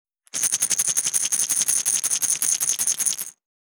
372,薬を振る,薬瓶,薬瓶振る,シャカシャカ,カラカラ,チャプチャプ,コロコロ,シャラシャラ,
効果音